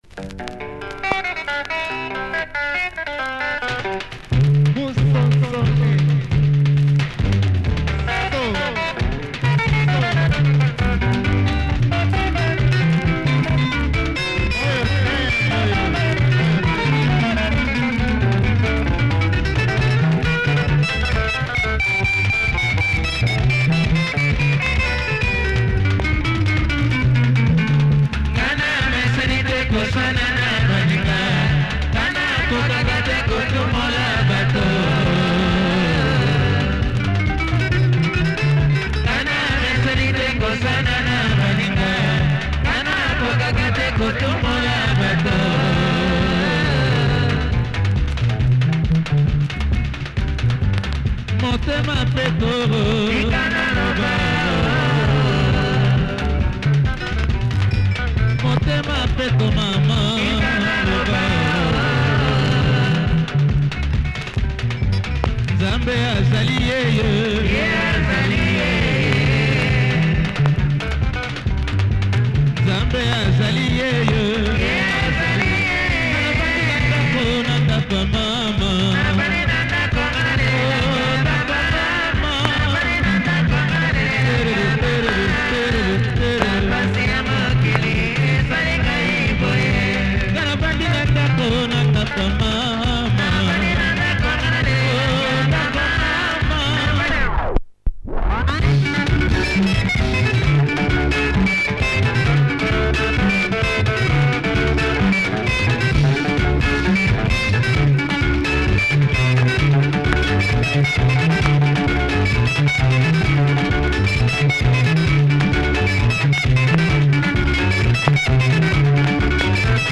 Interesting arrangements on this rough
Check audio progressive breakdown! https